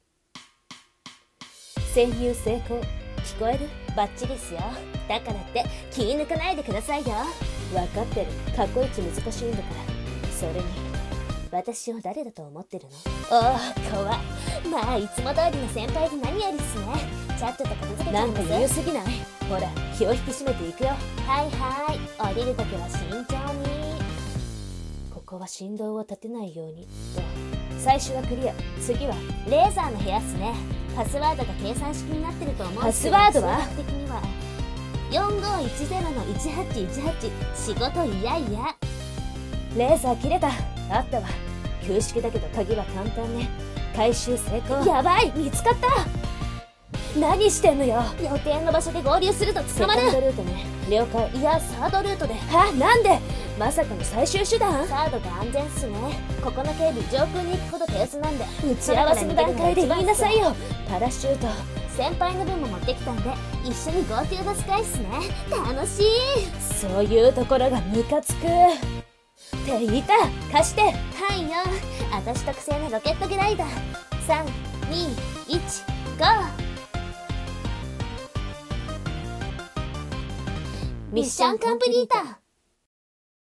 【声劇】蒼い空への逃飛行